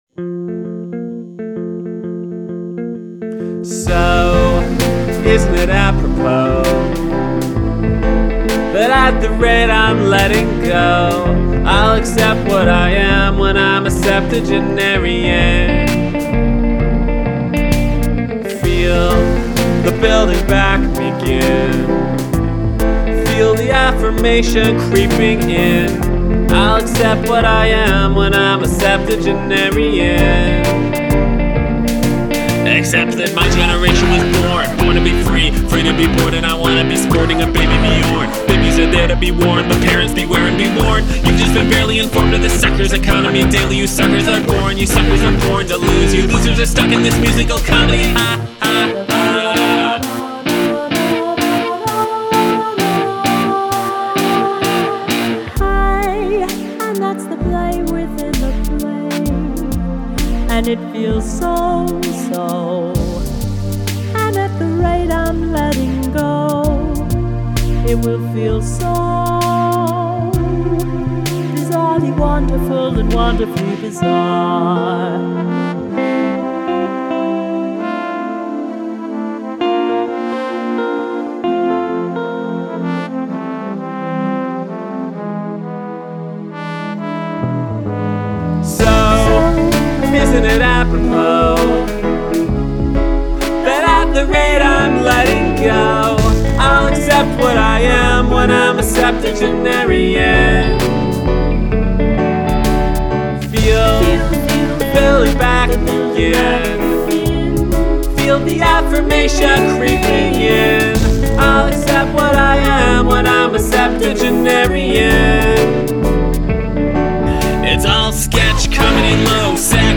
I will write and record one song a month,